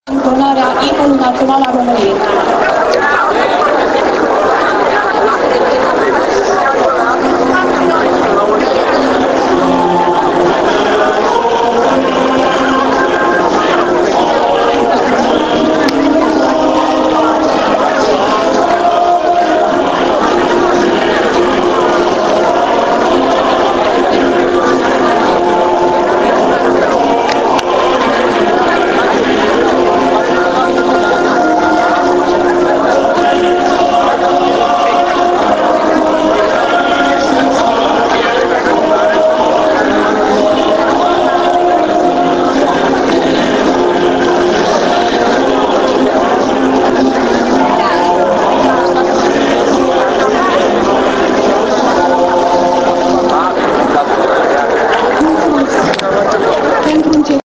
Imnul României la deschiderea noului an şcolar